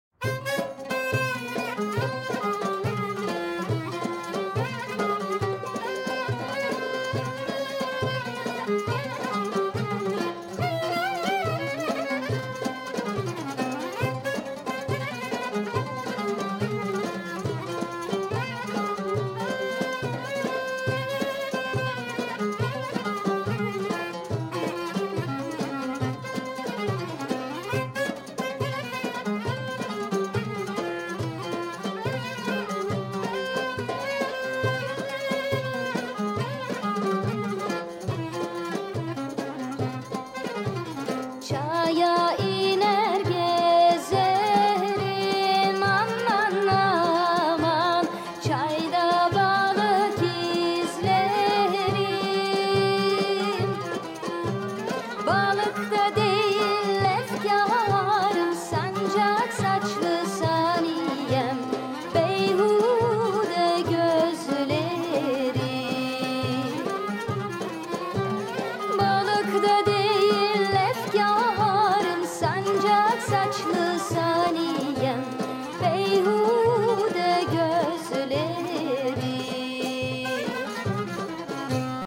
composiciones de reminiscencias otomanas
clarinetista